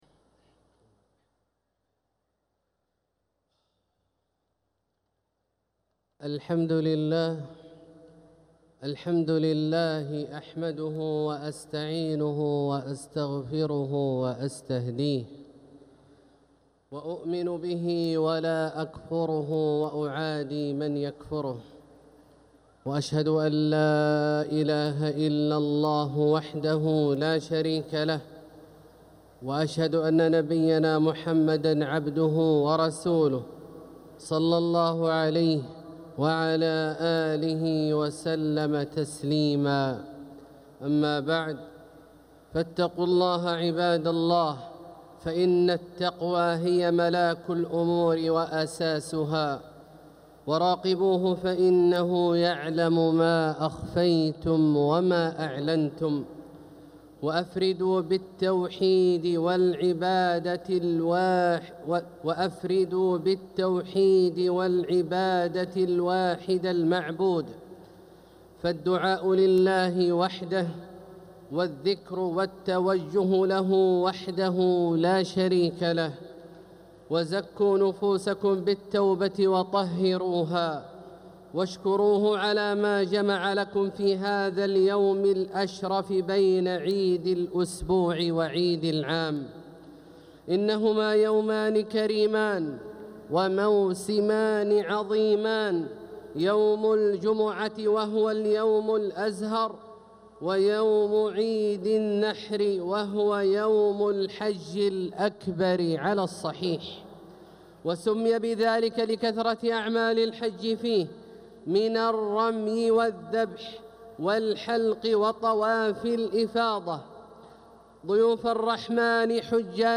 | Khutbah Jumu’ah 6-6-2025 > khutbat aljumuea > Miscellanies - Abdullah Al-Juhani Recitations